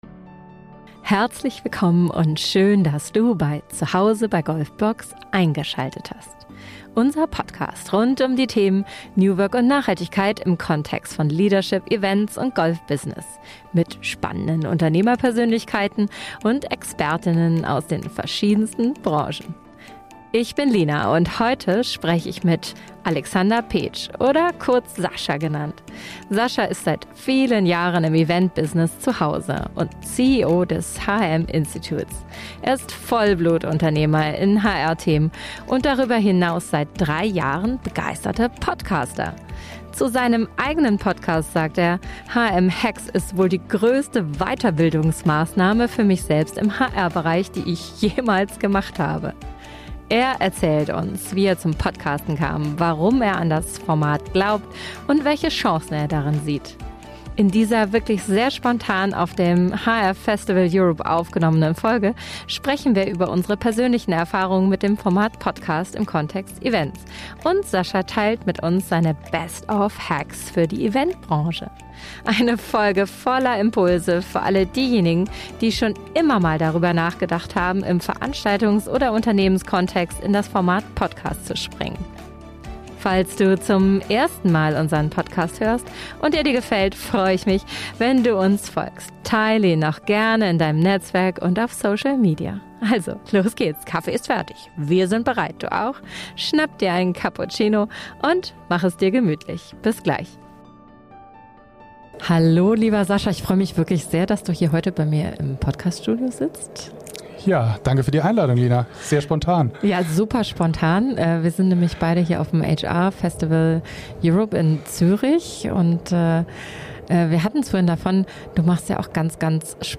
Viel Spaß beim Zuhören dieser energiegeladenen ehrlichen Folge.